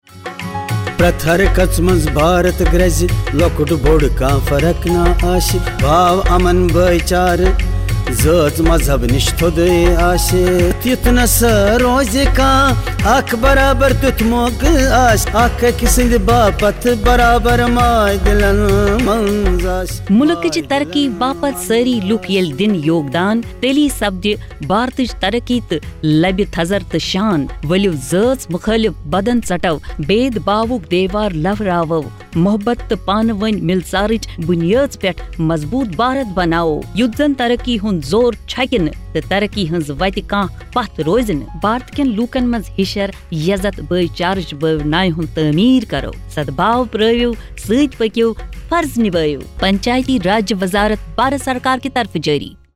84 Fundamental Duty 5th Fundamental Duty Sprit of common brotherhood Radio Jingle Kashmiri